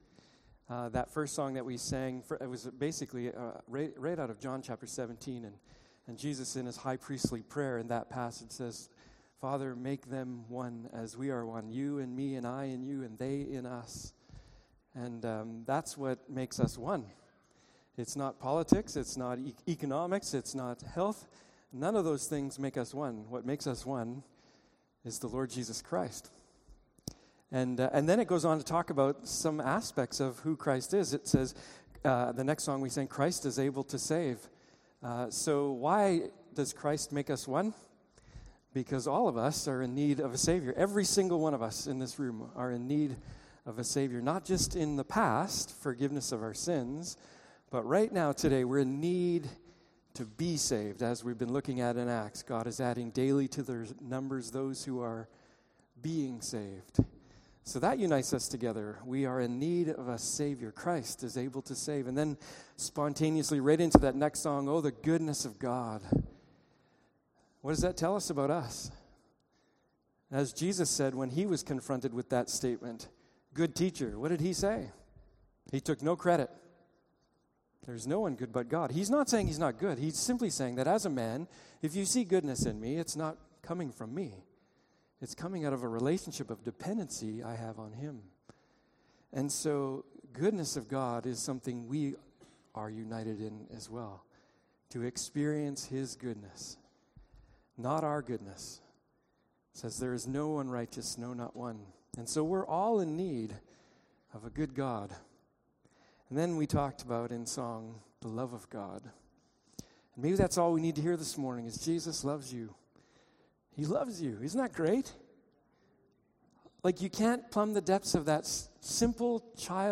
Passage: Matthew 16:21-26 Service Type: Morning Service